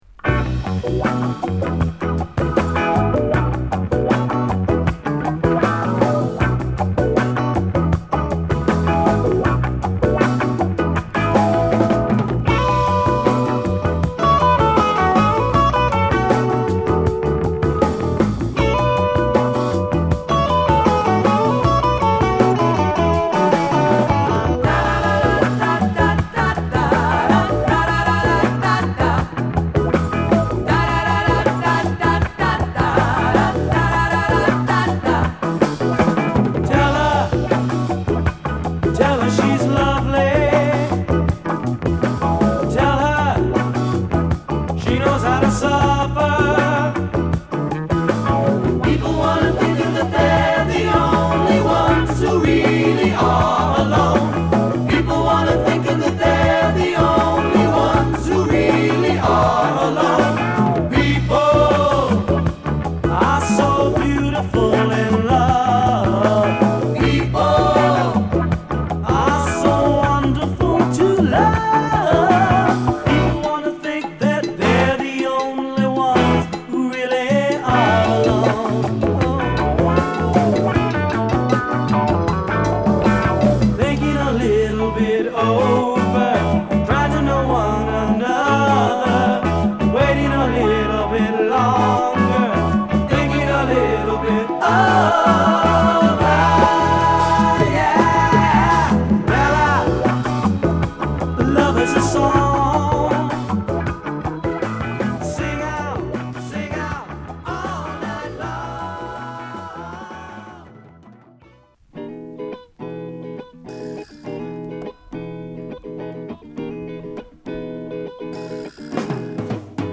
L.A.のチカーノロックグループ